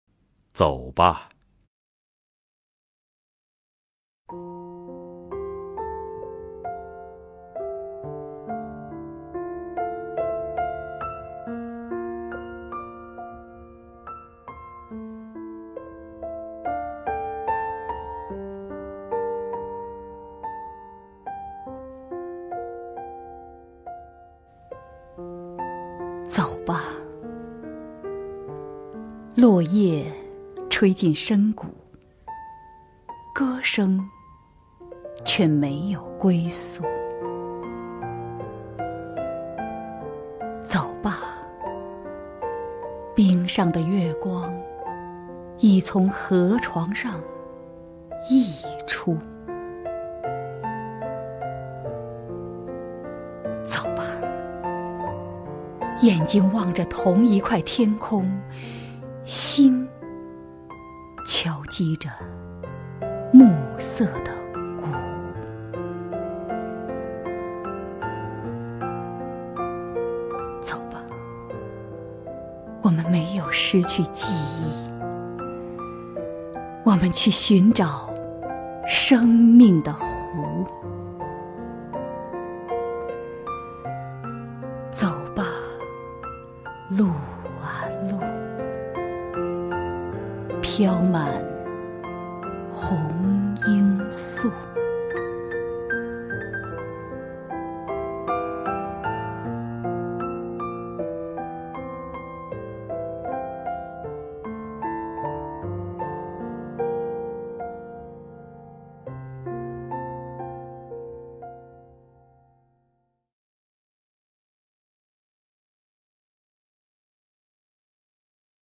狄菲菲朗诵：《回答》(北岛)
名家朗诵欣赏 狄菲菲 目录